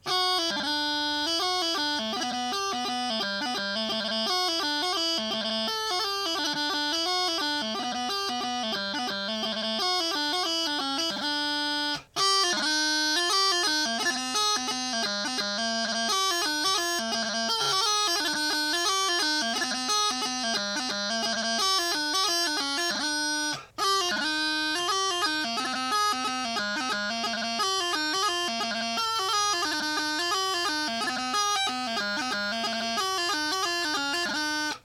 It is red and it sounds nice. It has a smoother tone than your average Walsh reed and while only slightly harder to blow, doesn’t come anywhere near what pressure is required for your average Gibson/Abbott reed. It is also flatter than the Walsh pc reed by a bit. Here’s a recording of the Naill pc Caberfeidh reed first, followed by a new longer bladed Walsh reed, and finally an older, well broken in Walsh reed that has smaller blades than the new variety.
PC reeds recording
pcreeds.wav